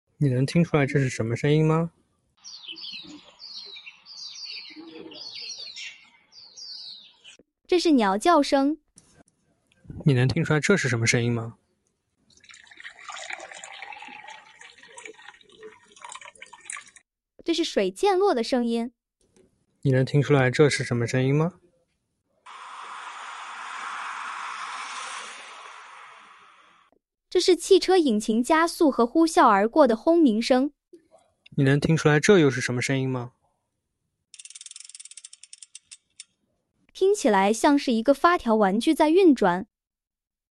总的来说，其生成的语音真人感比较强，停顿和语气都比较自然，但是Step-Audio 2 mini在信息识别上还需要加强。
在官方提供的案例中，Step-Audio 2 mini能分清鸟叫、流水、车声和发条玩具声这四种不同的声音，它甚至能听出汽车声中的引擎加速变化。